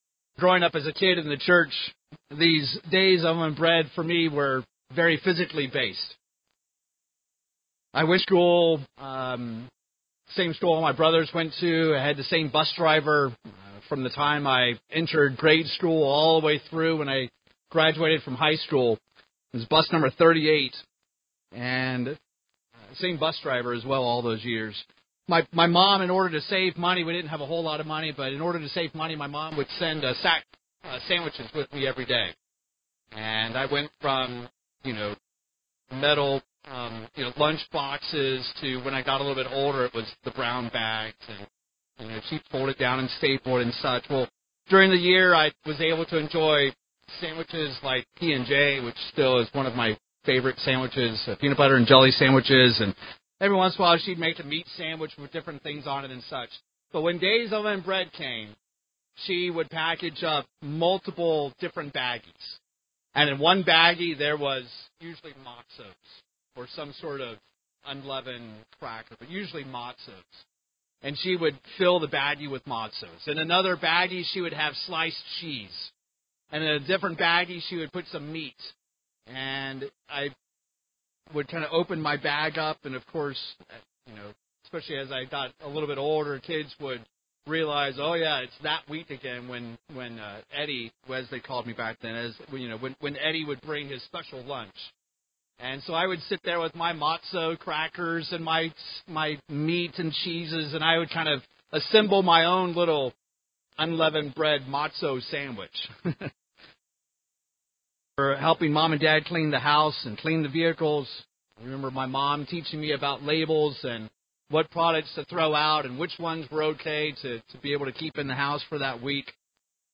Holy Day Services Studying the bible?